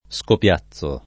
scopiazzo [ S kop L#ZZ o ]